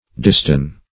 Distune \Dis*tune"\